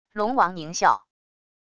龙王狞笑wav音频